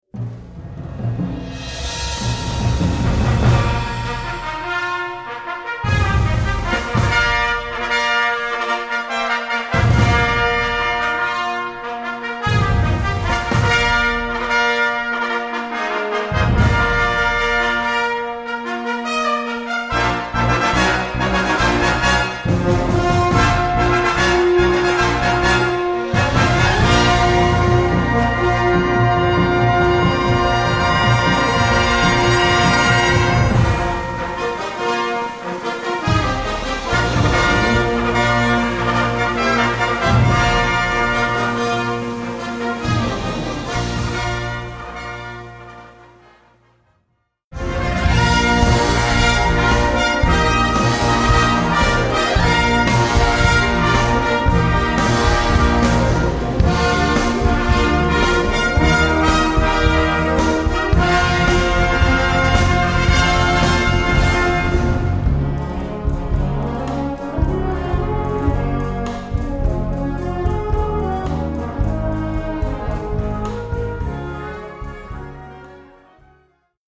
Gattung: Eröffnungswerk
Besetzung: Blasorchester